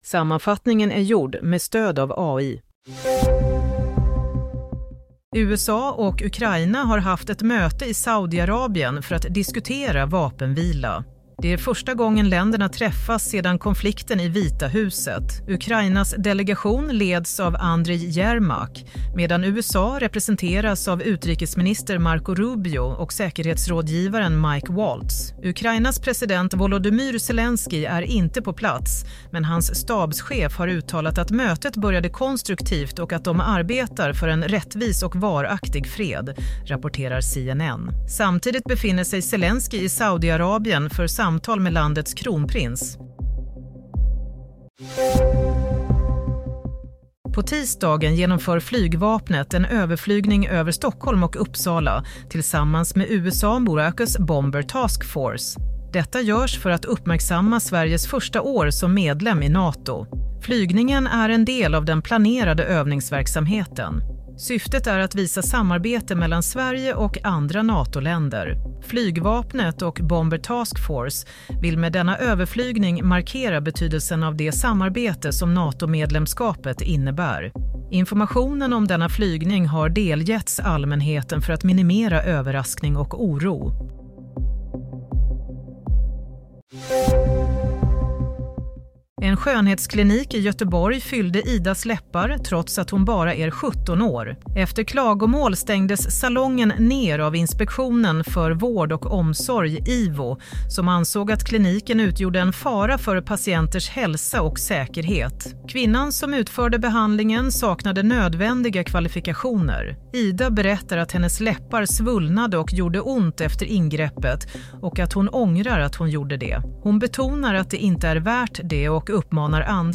Play - Nyhetssammanfattning – 11 mars 16:00
Sammanfattning av följande nyheter är gjord med stöd av AI.